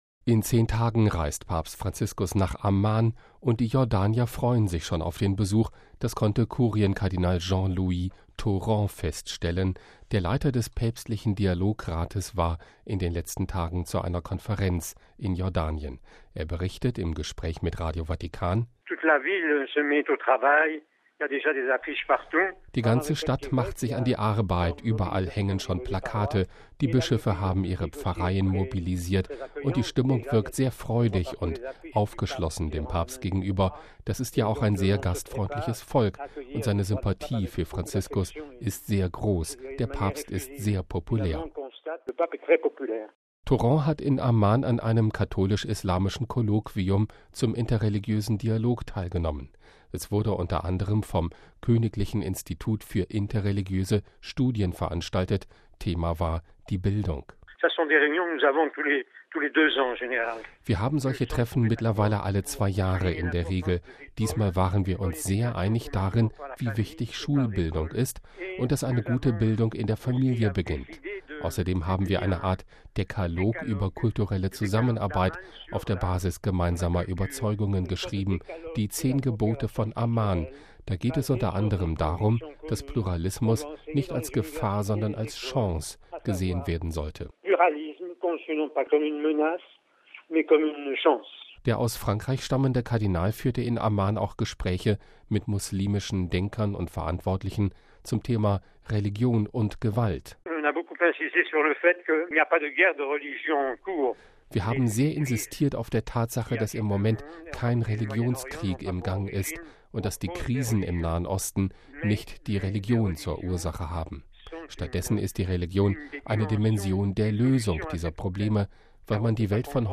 Er berichtet im Gespräch mit Radio Vatikan: